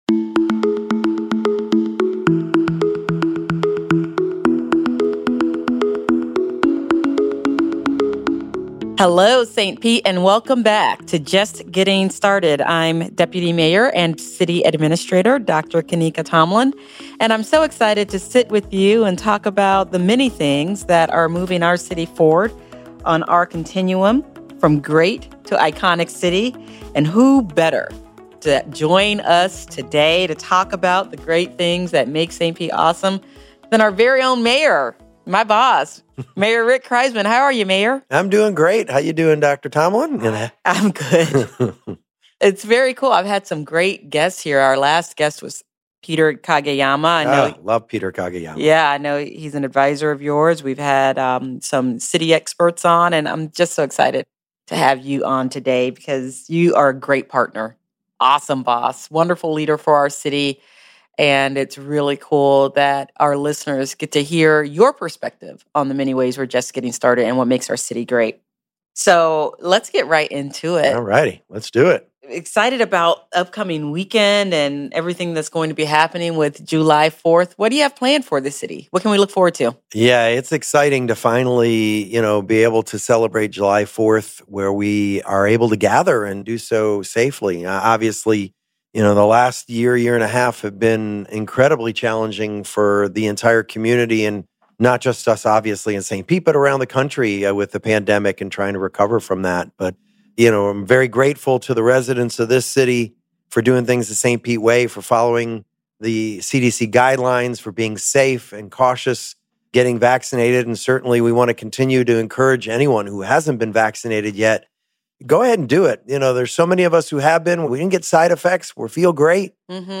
Dr. Tomalin's guest is St. Petersburg Mayor Rick Kriseman. Together, they discuss the St. Pete Pier, which will celebrate its first anniversary on July 6.